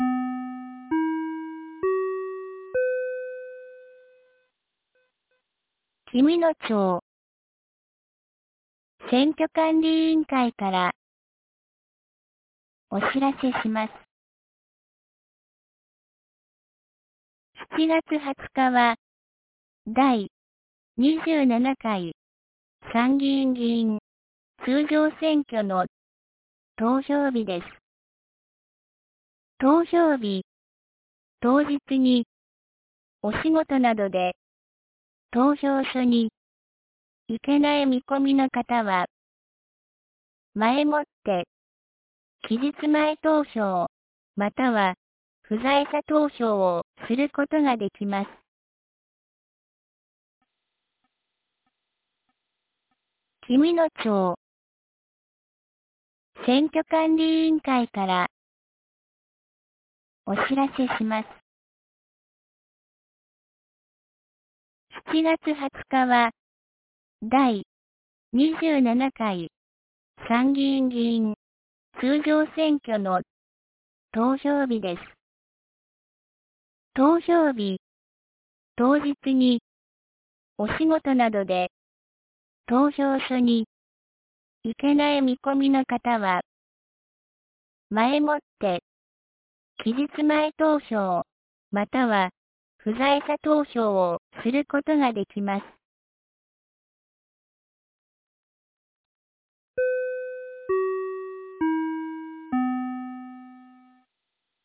2025年07月05日 17時06分に、紀美野町より全地区へ放送がありました。